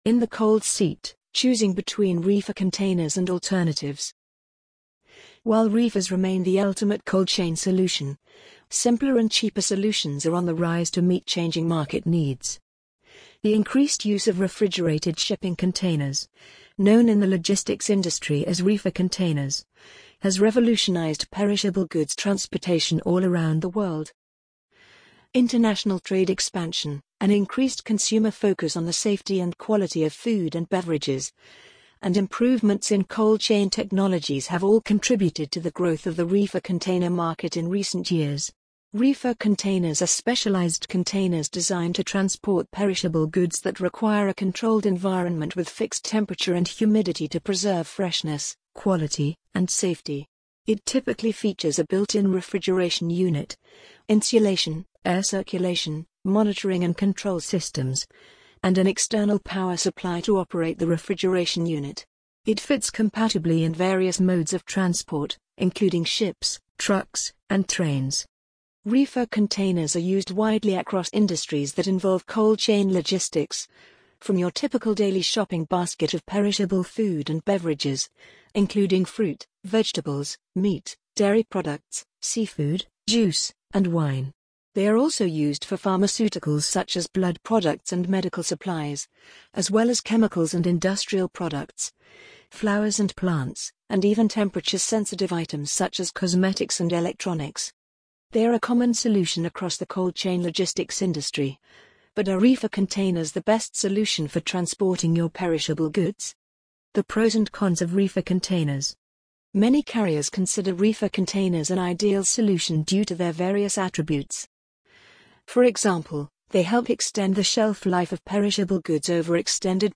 amazon_polly_48018.mp3